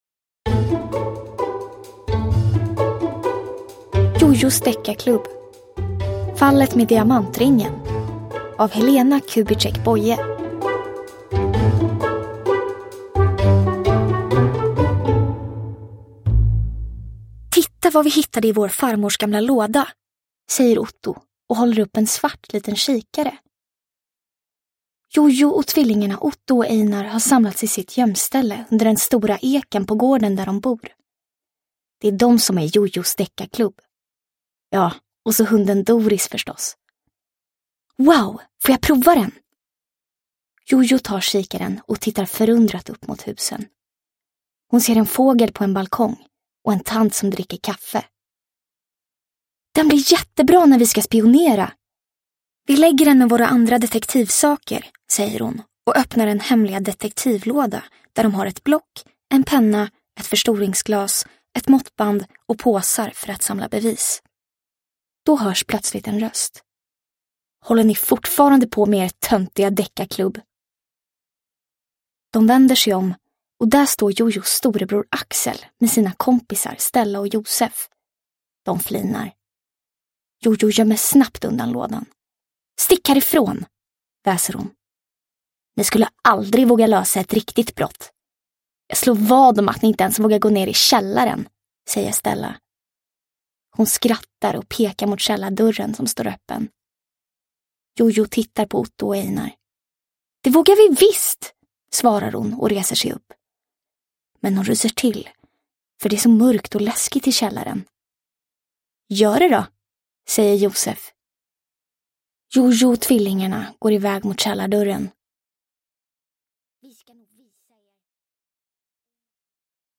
Fallet med diamantringen – Ljudbok – Laddas ner